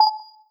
menu-freeplay-click.wav